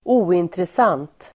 Uttal: [²'o:intresan:t (el. -ang:t)]